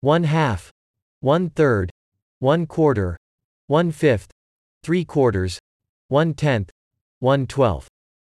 Bien prononcer les fractions en anglais: